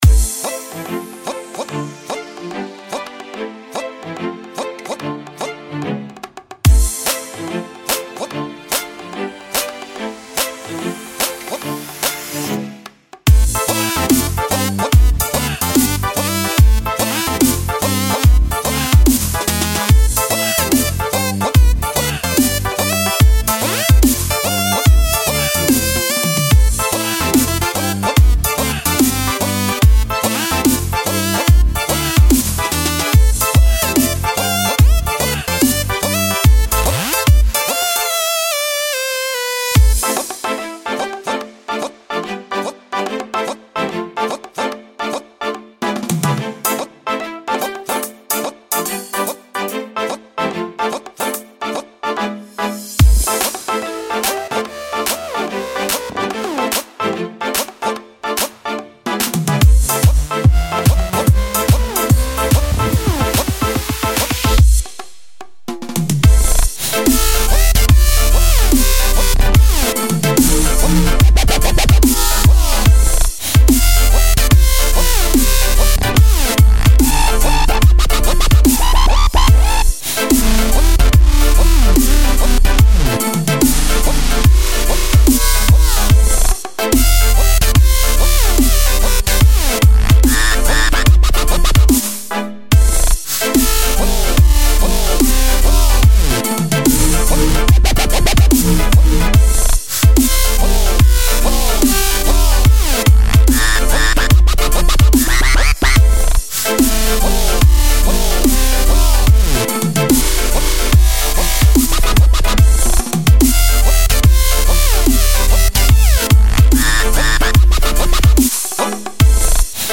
Žánr: Electro/Dance
Genres: Dubstep, Music, Electronic, Dance